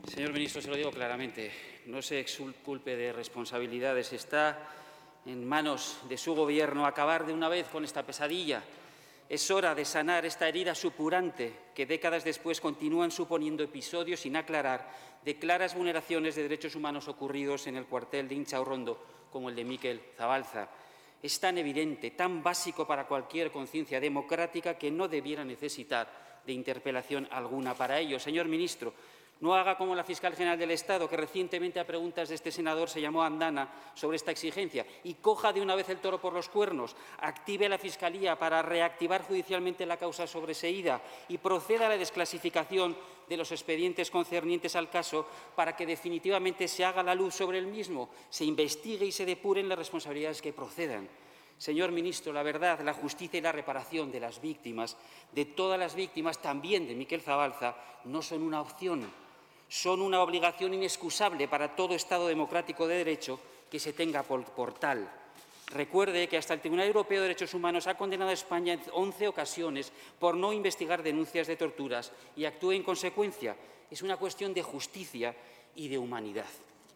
En su pregunta en la sesión de control al Ejecutivo, también ha pedido que se desclasifiquen los expedientes concernientes al caso para que definitivamente se aclare, se investigue y se depuren las responsabilidades que procedan.